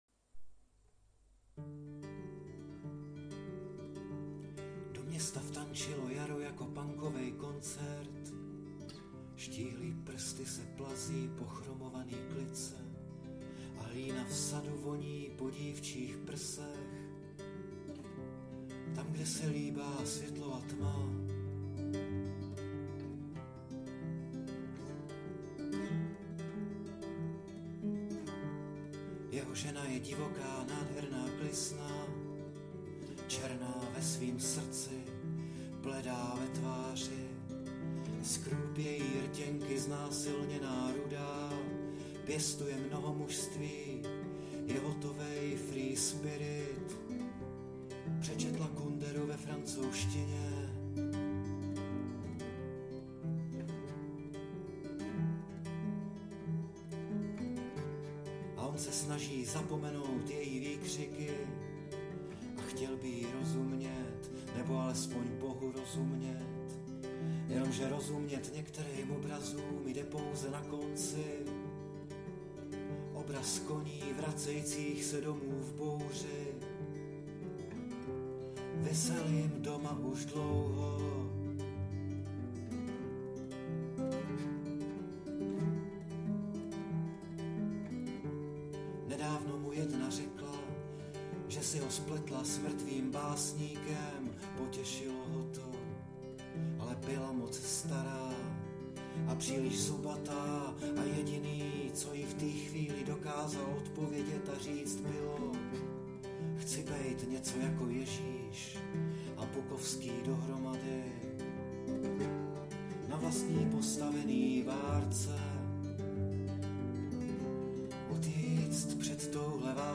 Anotace: Jedna starší, nepřipoutaná rýmy, odžitá do poslední strofy, v hudebním kabátku.
Smůla, nahrál jsem to na španělku přesně tak, jak to zní.